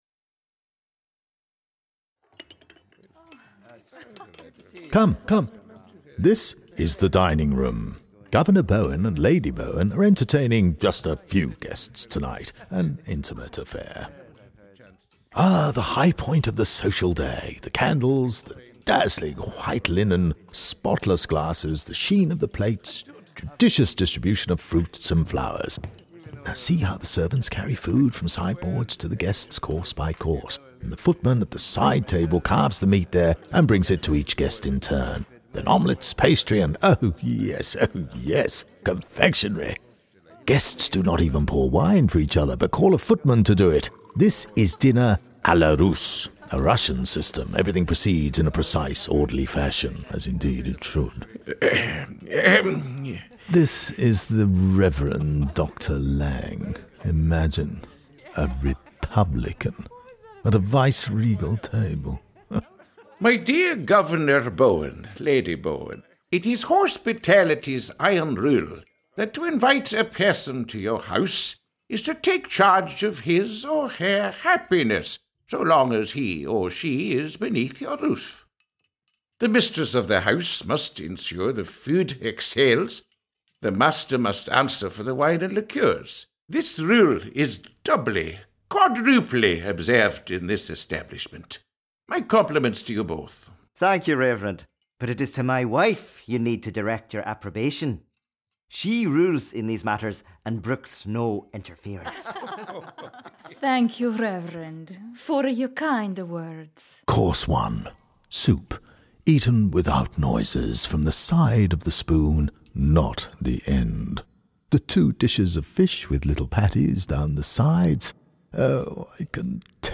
Led by the ghost of Governor Musgrave, who died in the House, the podcast tour guides you through key moments in the life of the House with audio dramatisations of exchanges that would have occurred between the characters of 19th century Queensland.